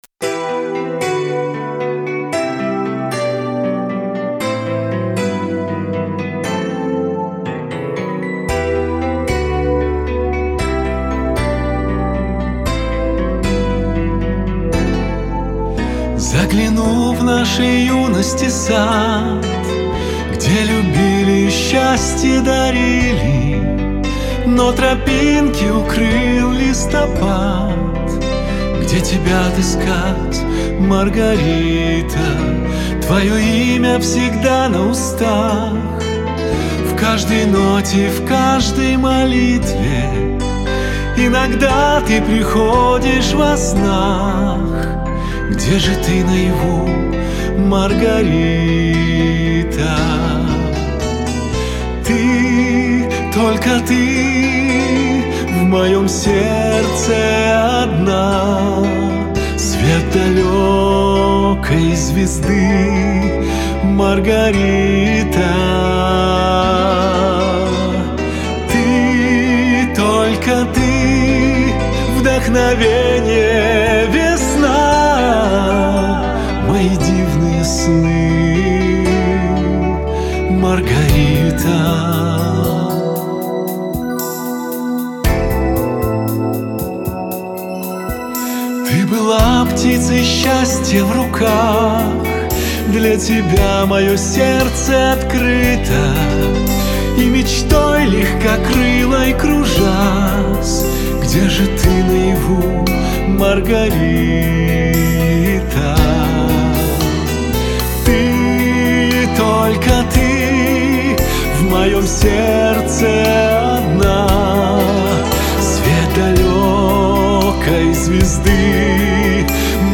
с невероятно нежной, красивой и завораживающей мелодией